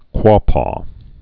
(kwôpô)